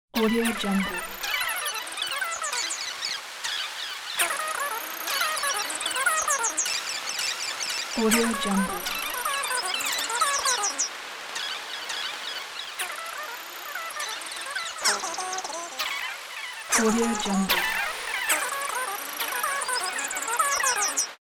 دانلود افکت صوتی مکالمه رادیویی موجود فضایی کارتونی
Cartoon Alien Radio Chattering royalty free audio track is a great option for any project that requires cartoon sounds and other aspects such as an alien, cartoon and chattering.
Sample rate 16-Bit Stereo, 44.1 kHz
Looped No